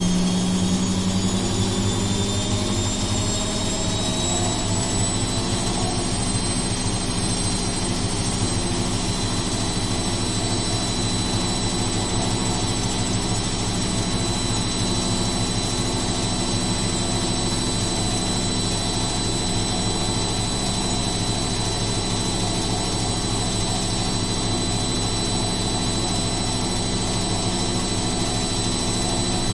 PUNK ROCK鼓 200 bpm
标签： 岩石 RU bbish 轨道 速度快 朋克 后盾
声道立体声